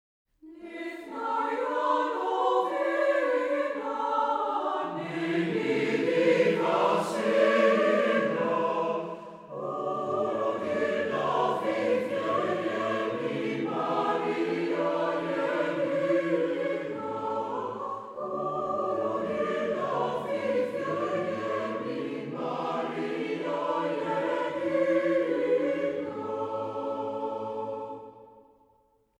Carol.
Orthodox song.
Tonality: B flat major